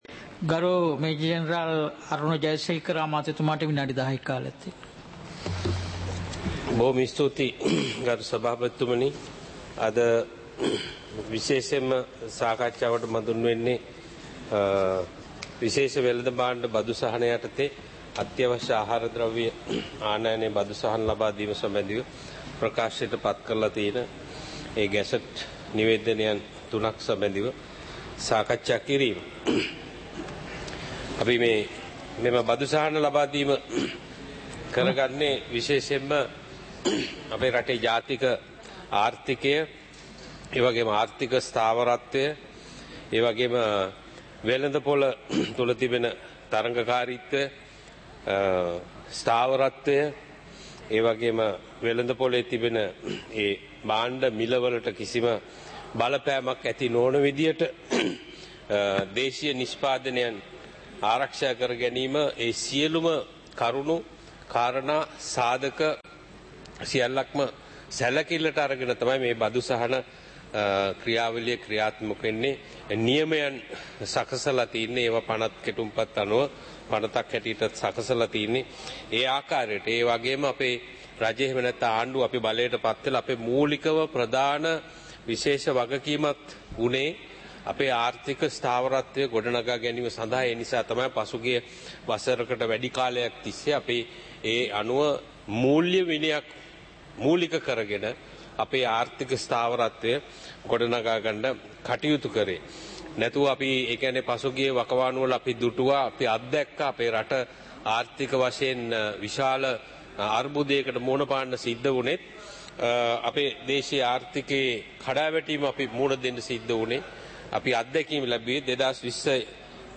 சபை நடவடிக்கைமுறை (2026-02-18)